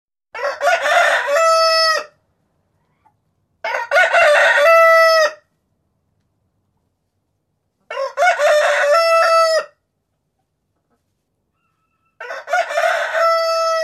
Klingelton Rooster Alarm
Kategorien Alarm